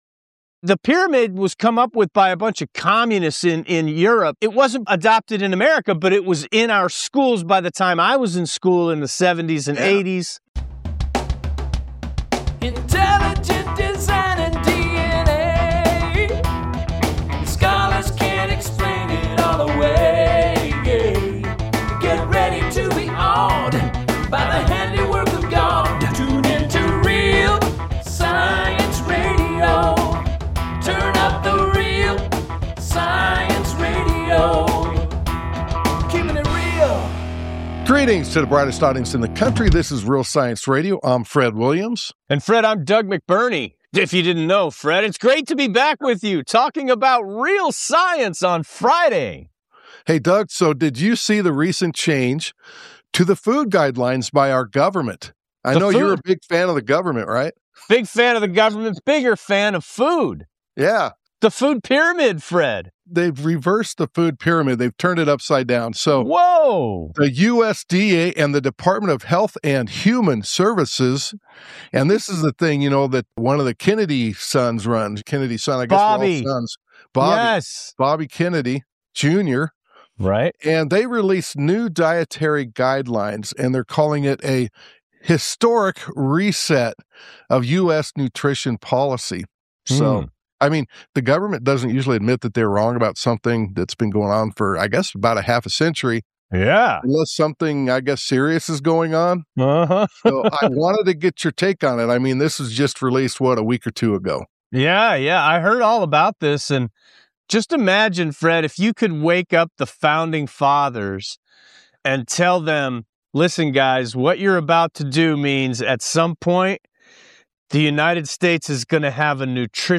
Friday's Broadcast